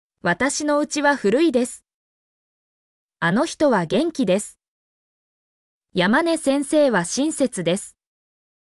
mp3-output-ttsfreedotcom_LRfslSlr.mp3